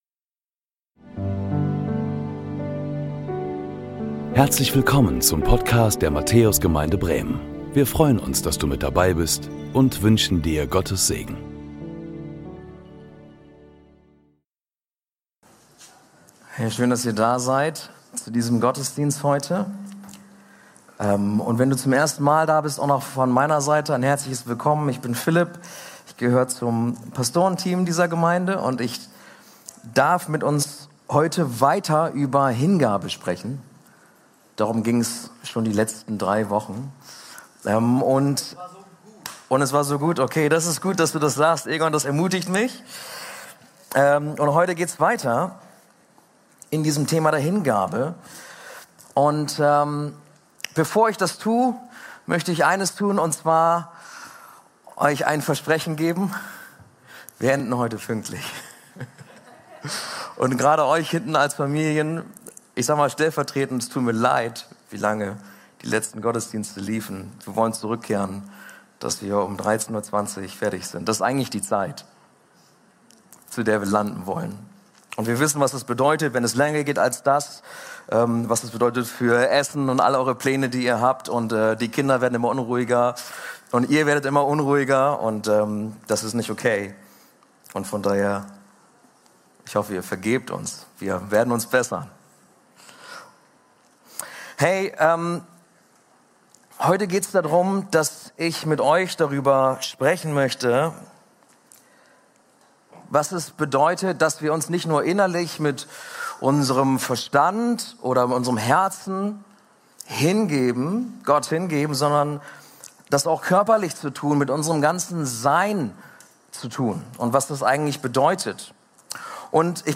Heute setzen wir unsere Predigtreihe fort, in der es um völlige Hingabe geht. Wir freuen uns, dass du bei unserem modernen, 2. Gottesdienst dabei bist!